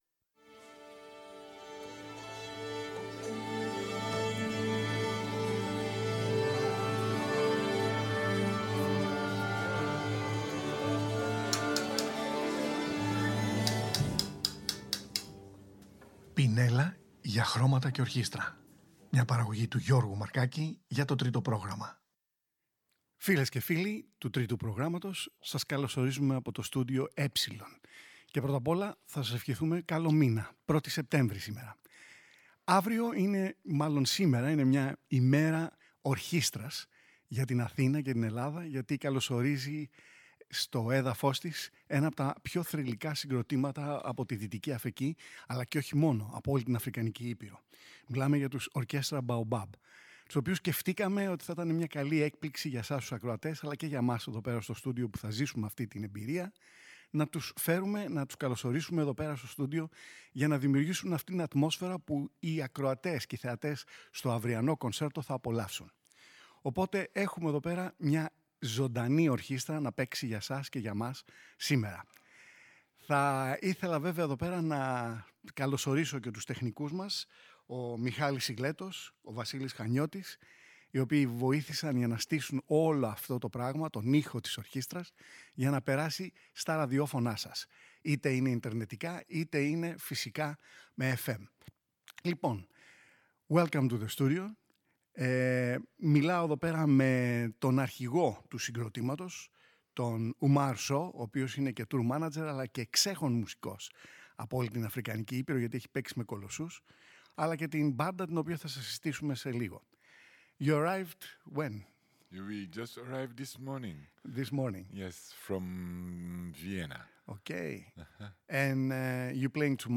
ζωντανά από το στούντιο “E”
με ένα μικρό κοντσέρτο.
συνομιλεί με τους μουσικούς που απαρτίζουν το συγκρότημα